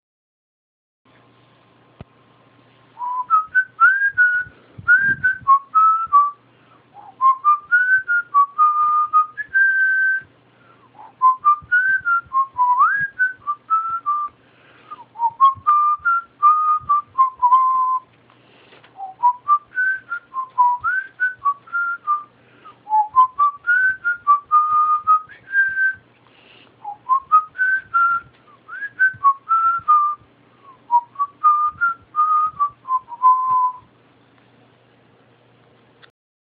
НАСВИСТЕЛ, КАК МОГ.